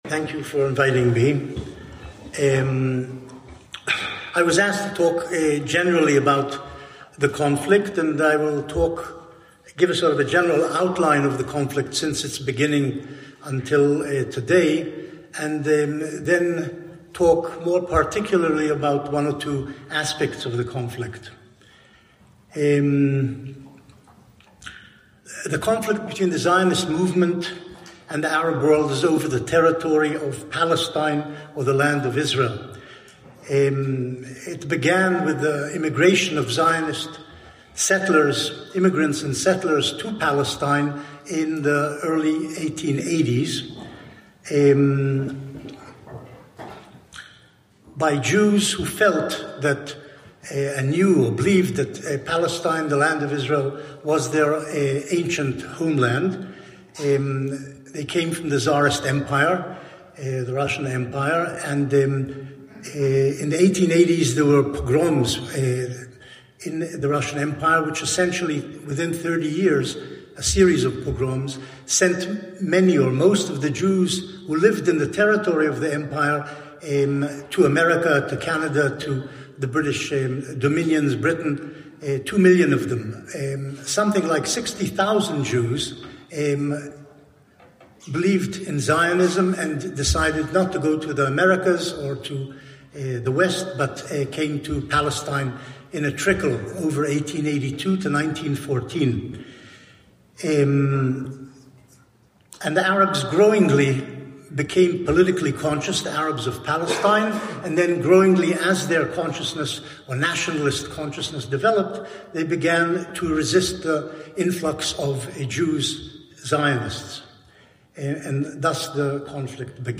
Vortrag Benny Morris ~ Der Mena-Talk Podcast
Beschreibung vor 1 Jahr Vortrag von Benny Morris am 4. Dezember 2024 im Jüdischen Gemeindehaus in Berlin. Eine Veranstaltung der Deutsch-Israelischen Gesellschaft (DIG) Berlin und Brandenburg e.V. und des Jungen Forums der DIG Berlin und Brandenburg.